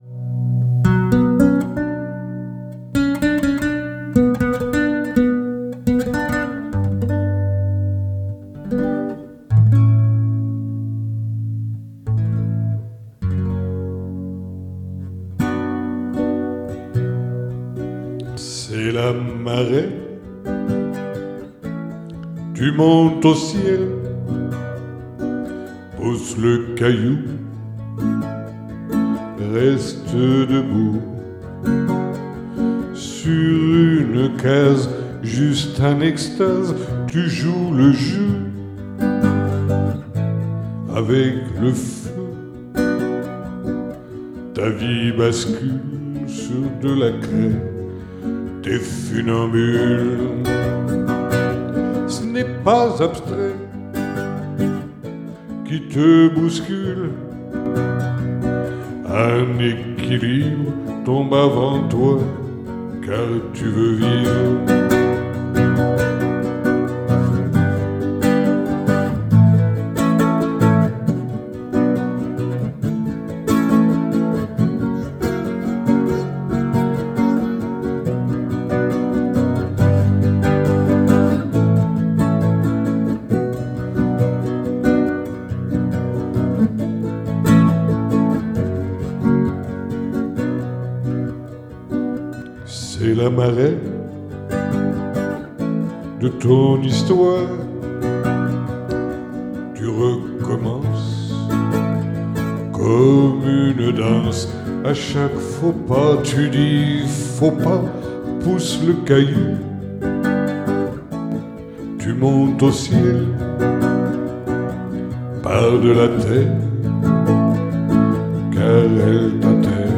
Petite intro